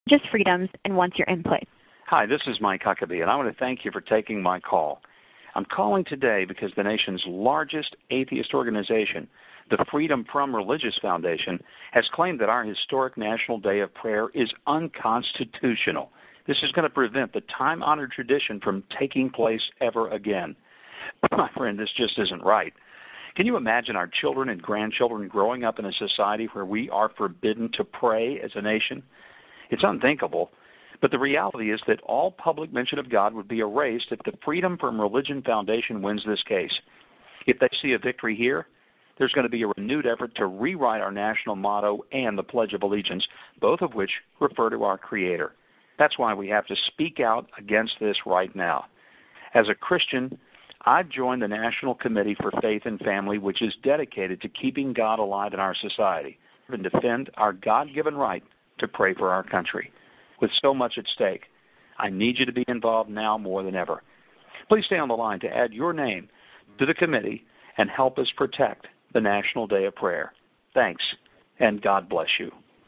Ex-governor of Arkansas and FOX TV commentator Mike Huckabee has recorded a fundraising robocall pitch which disses the Freedom From Religion Foundation for “the National Committee for Faith and Family.”
huckabee-robocall.mp3